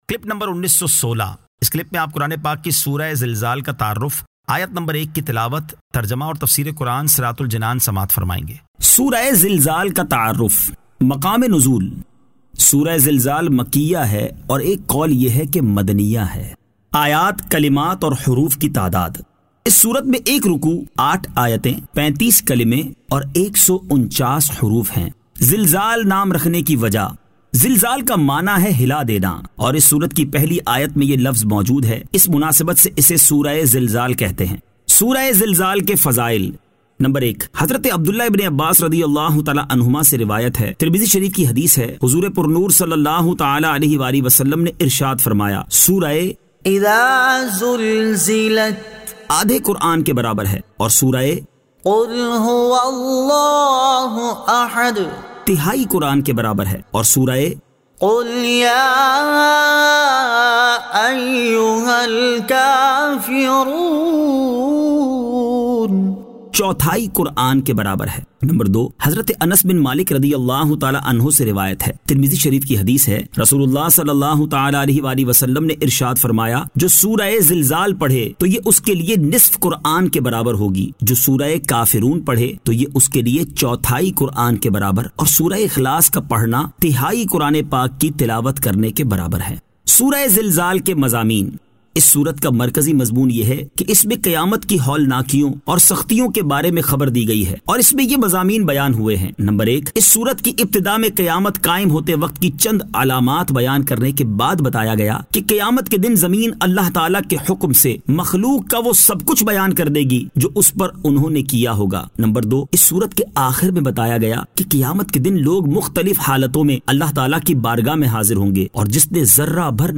Surah Al-Zilzal 01 To 01 Tilawat , Tarjama , Tafseer